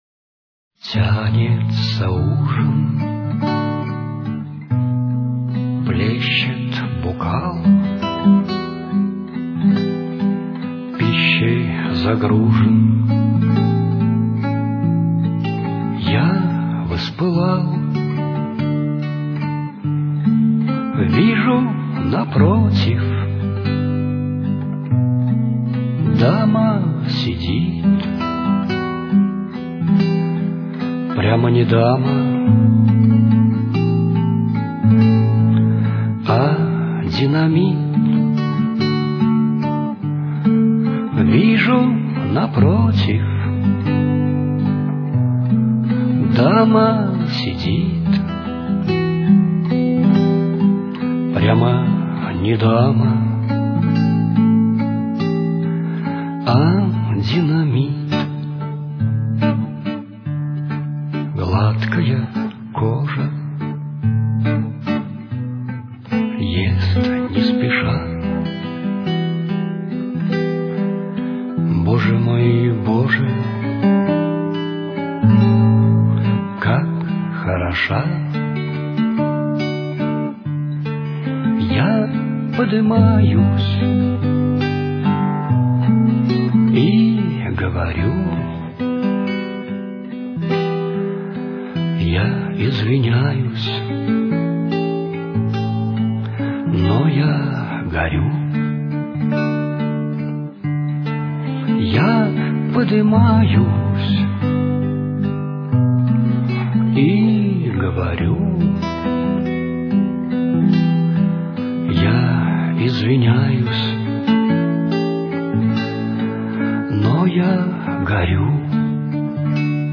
современная бардовская песня